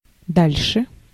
Ääntäminen
IPA : /ˈfɜː(ɹ).ðə(ɹ).mɔː(ɹ)/